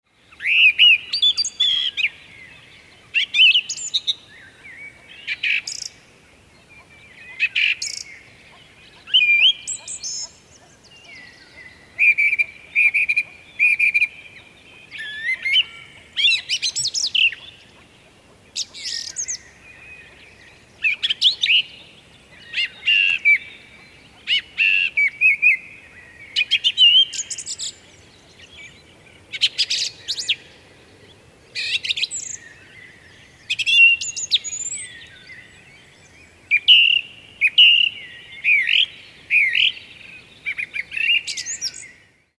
laulur2stas.mp3